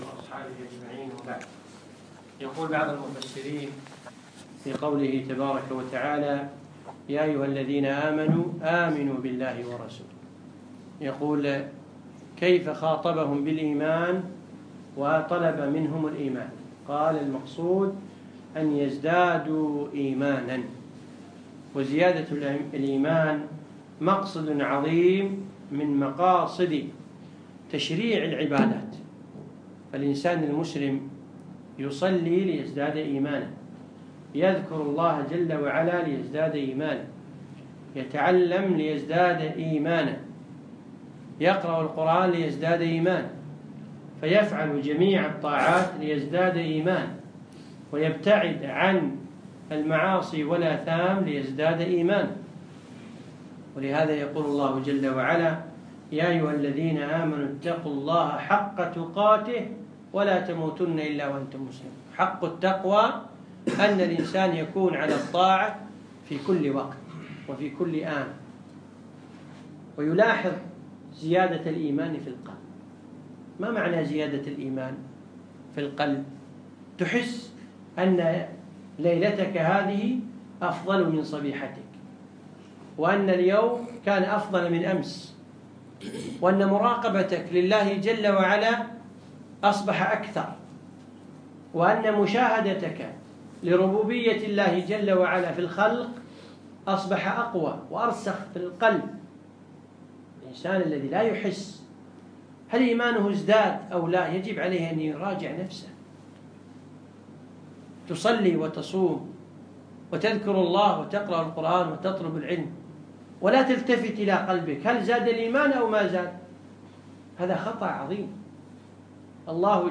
موعظة ( أنظر إلى قلبك !!!)